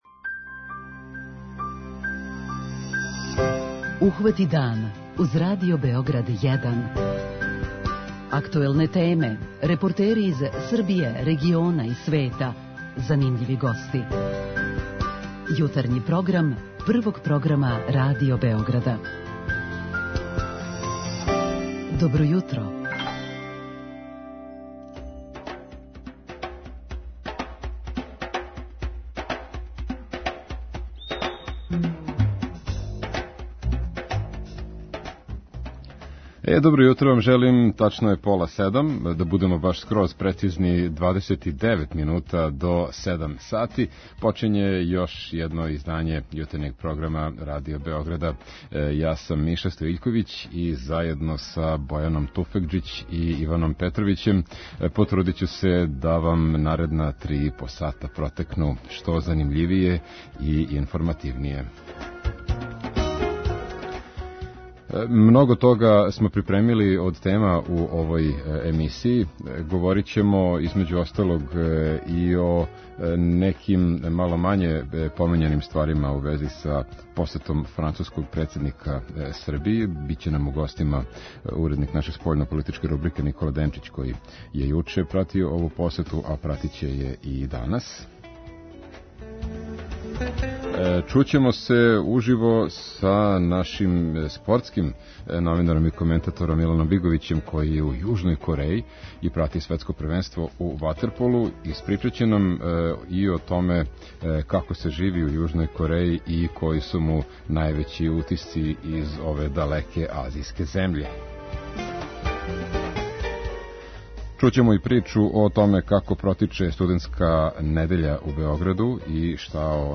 преузми : 37.73 MB Ухвати дан Autor: Група аутора Јутарњи програм Радио Београда 1!